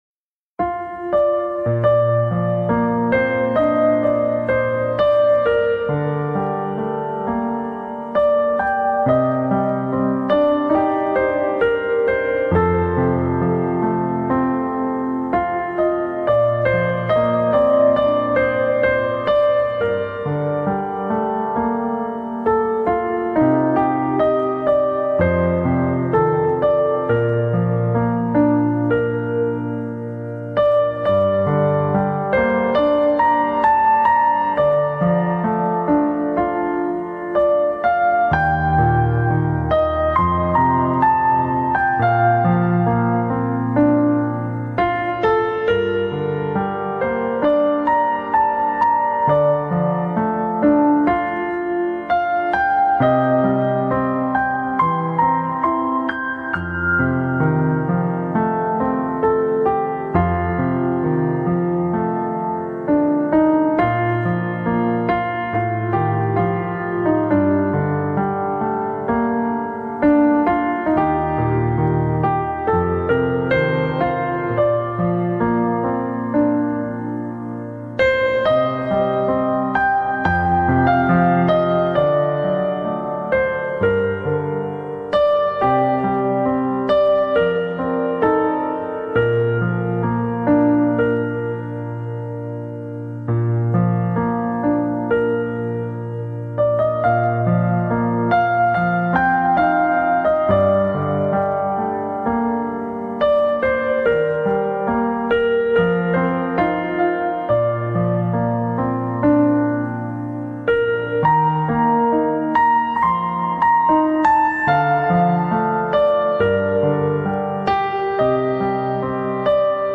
此为母带1比1版本  音质较好
07 母亲像一条河 (钢琴独奏版)